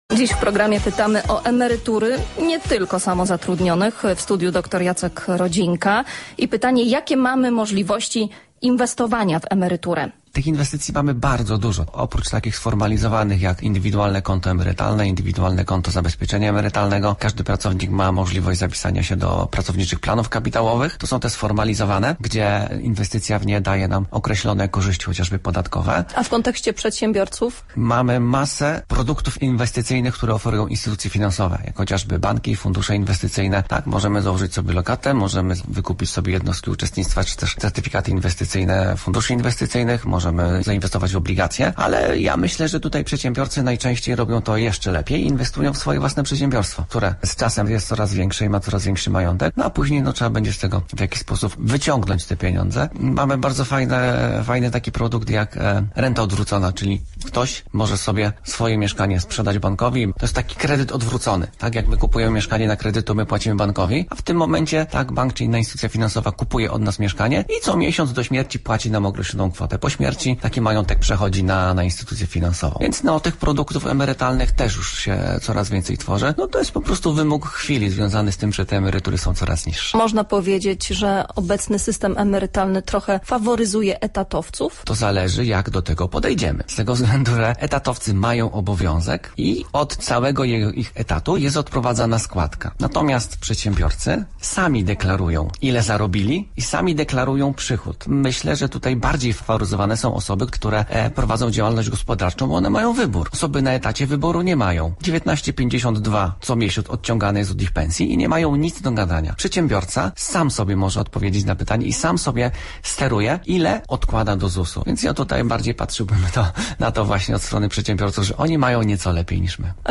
mówił na antenie radia